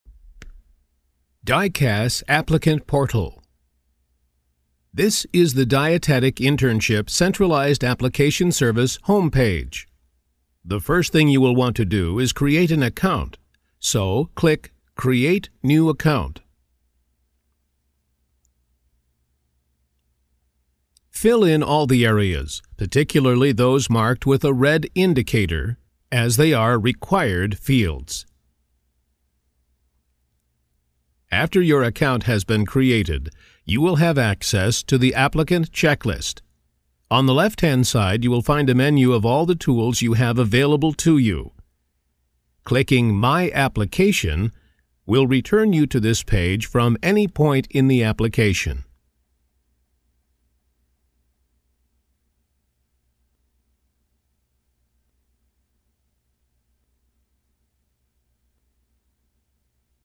Bright and friendly, or deliberate and commanding.
Sprechprobe: eLearning (Muttersprache):